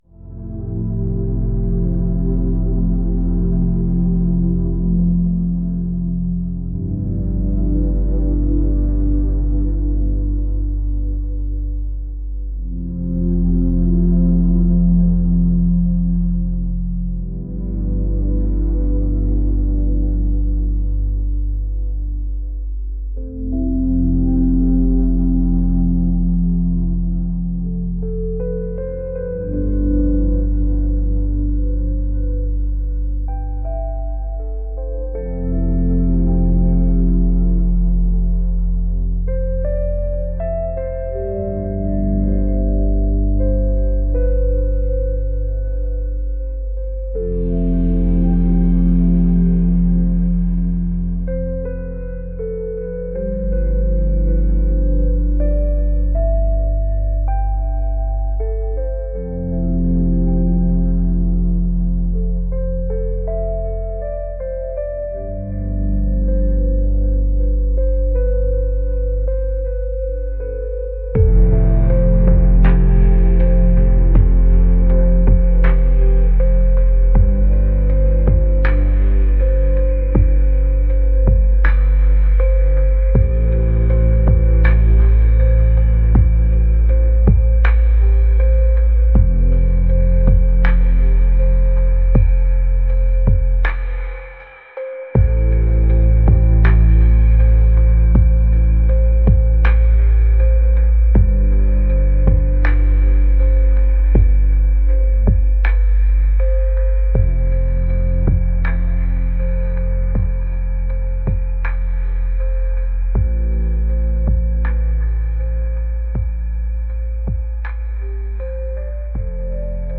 ambient | dreamy | ethereal